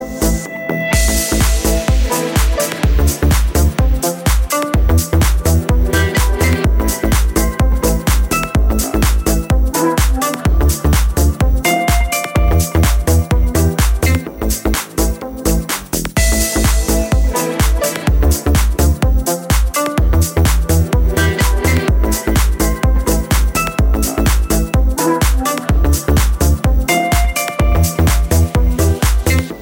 • Качество: 320, Stereo
инструментальные
спокойная музыка